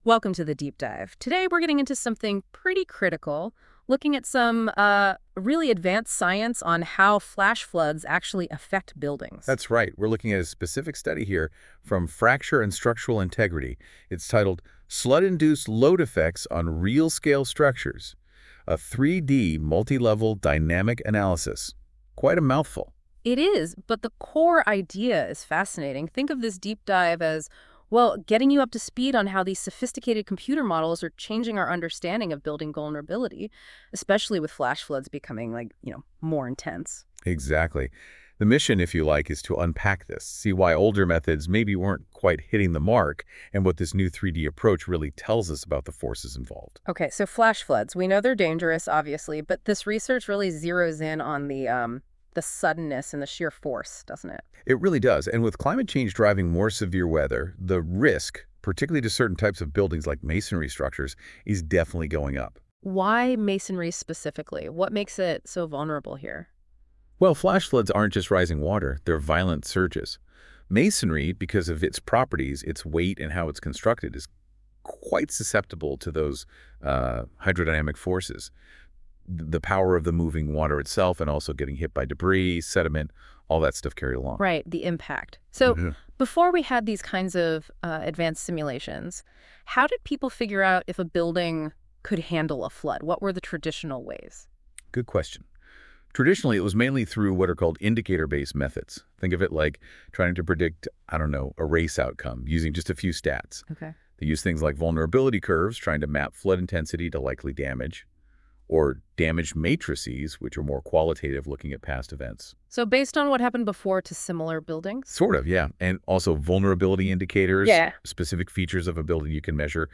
Join us for insightful interviews with top researchers, in-depth discussions of groundbreaking papers, and explorations of emerging trends in the field.